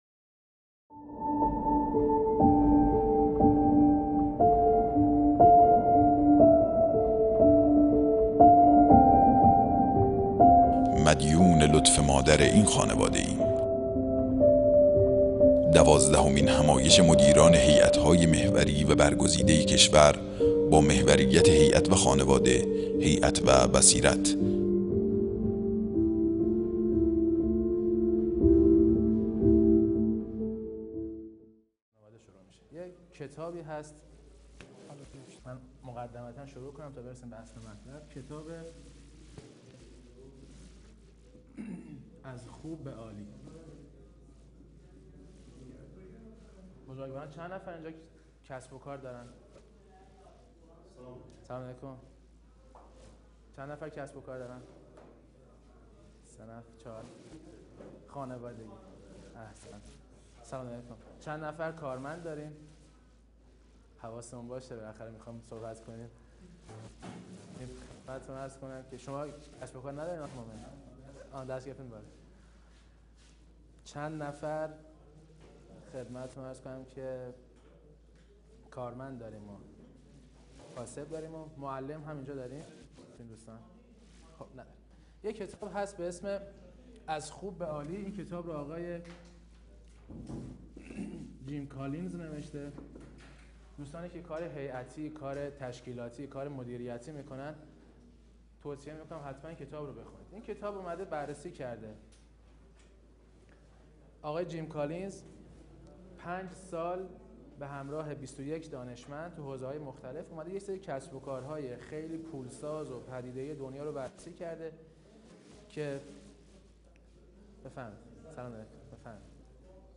کمیسیون تخصصی
شهر مقدس قم - مسجد مقدس جمکران